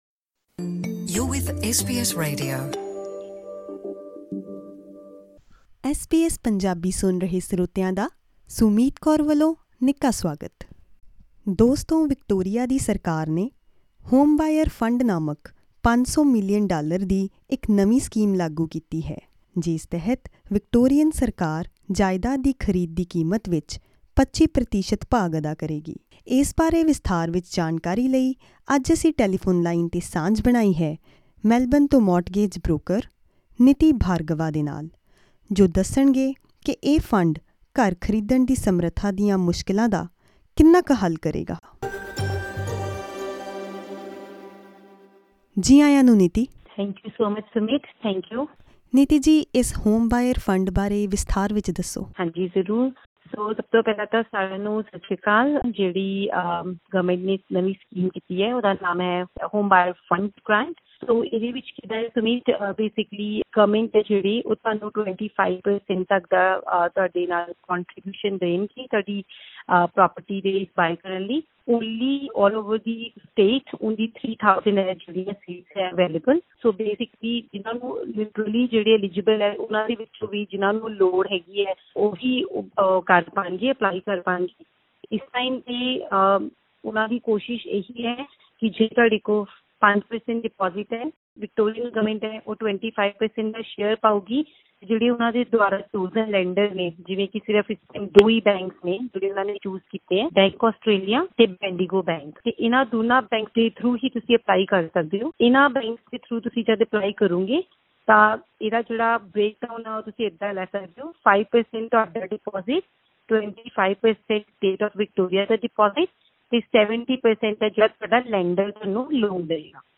Is Victoria's Homebuyers Fund a good option for buyers? Mortgage broker answers questions